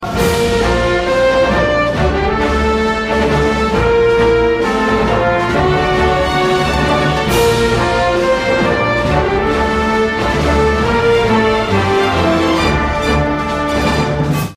Nhạc Chuông War Alarm